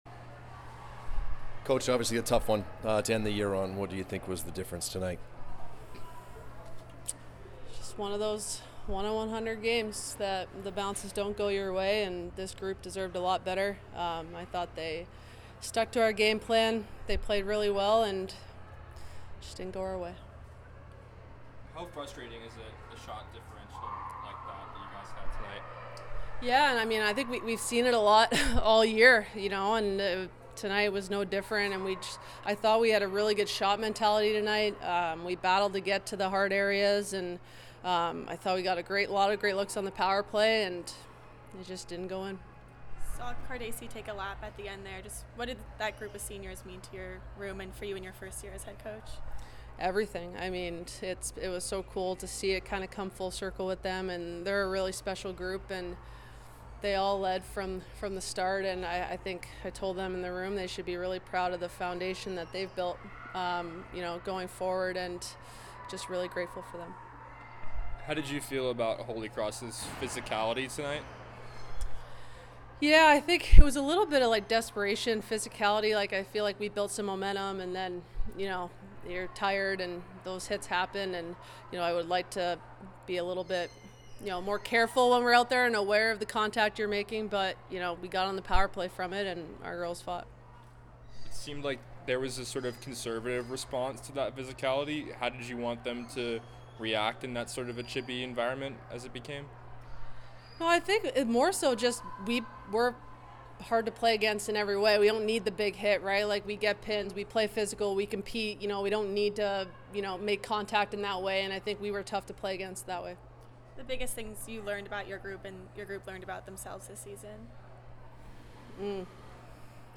Holy Cross Postgame